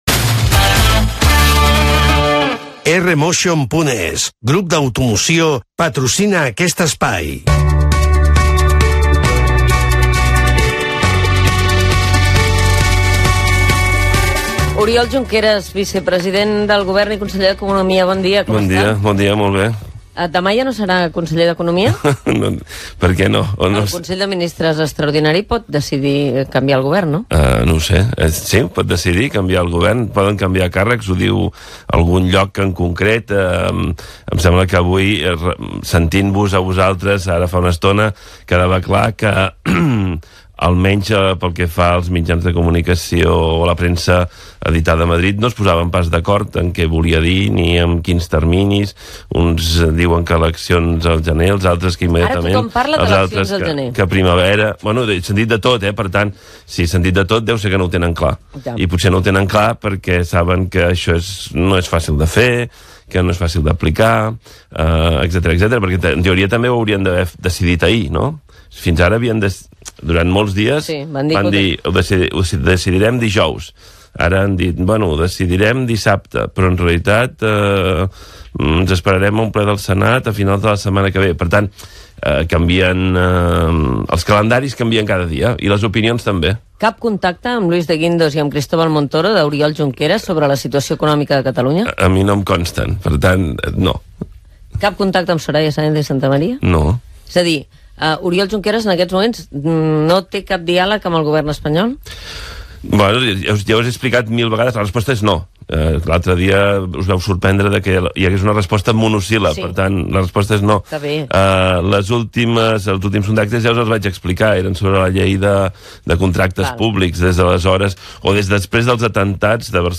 dcfec30c4be536dc28bf08ba705ecc3aaa876780.mp3 Títol Catalunya Ràdio Emissora Catalunya Ràdio Cadena Catalunya Ràdio Titularitat Pública nacional Nom programa El matí de Catalunya Ràdio Descripció Publicitat. Entrevista al polític Oriol Junqueras, vicepresident del Govern de la Generalitat i Conseller d'Economia hores abans de l'aplicació de l'article 155 de la Constitució espanyola per part del govern de l'Estat. S'hi parla d'aquesta qüestió i de les empreses que mouen les seves seus socials fora de Catalunya.
Info-entreteniment